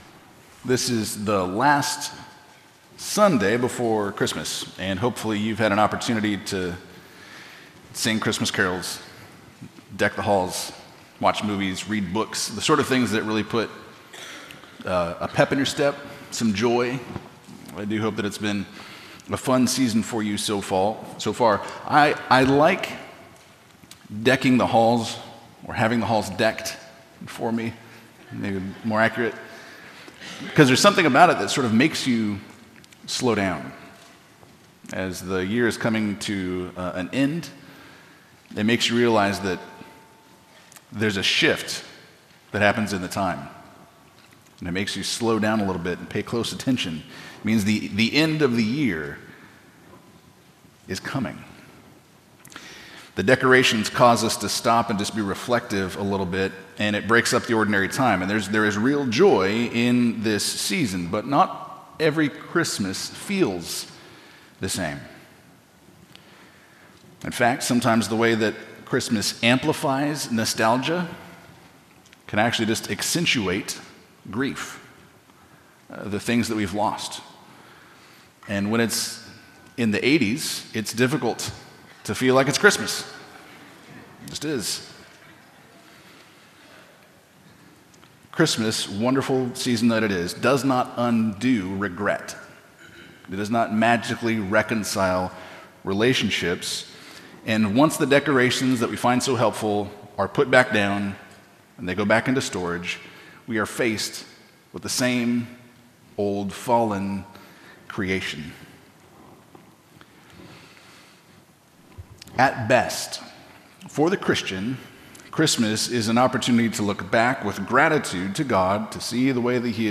Expositional Preaching from Trinity Bible Church in Phoenix, Arizona